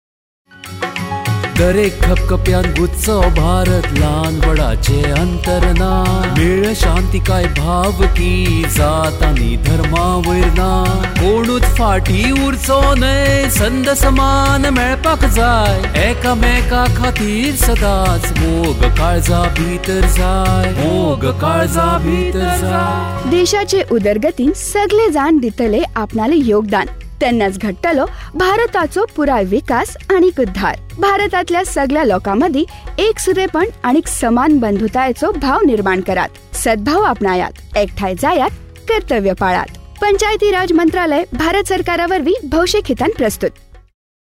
106 Fundamental Duty 5th Fundamental Duty Sprit of common brotherhood Radio Jingle Konkani